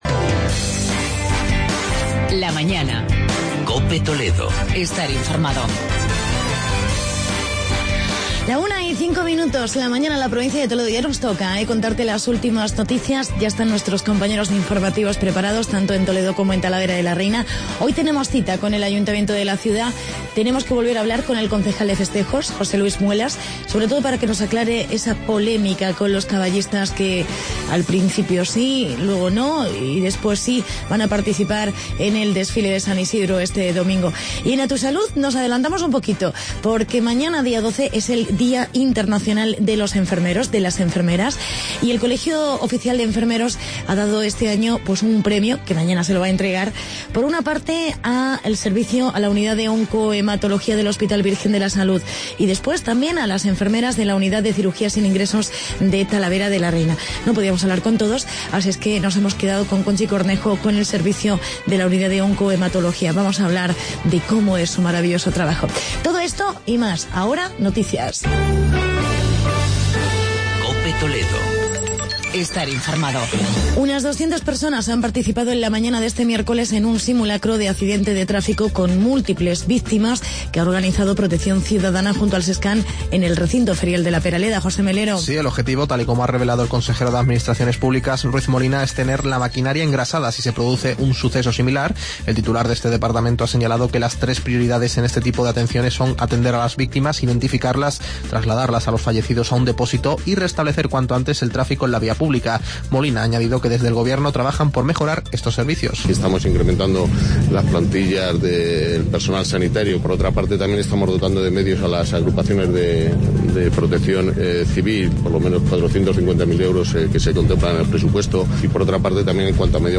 Entrevista con el concejal de festejos José Luis Muelas sobre las Ferias de Talavera y en "A Tu Salud" hablamos...